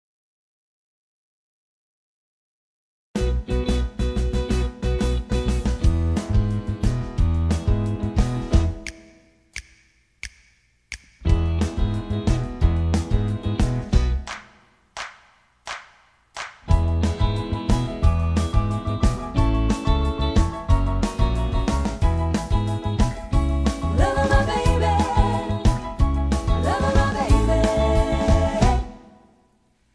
karaoke , sound tracks , backing tracks